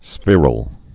(sfîrəl)